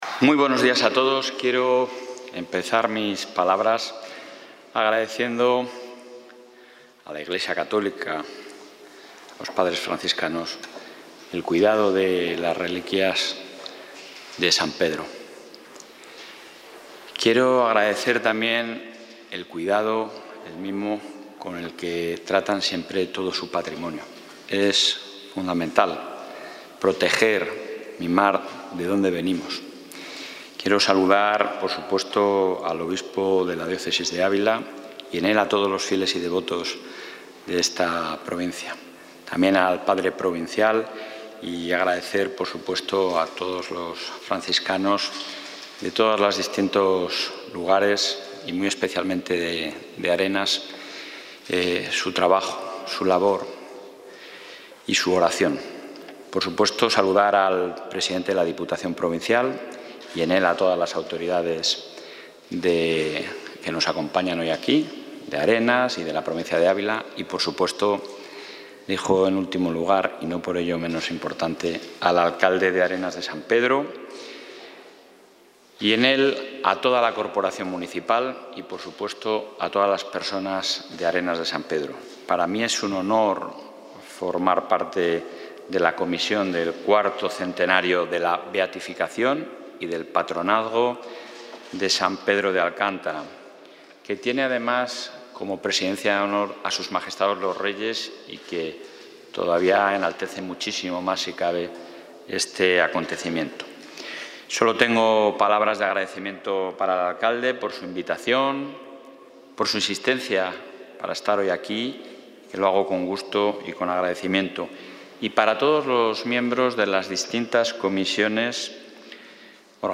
Intervención presidente.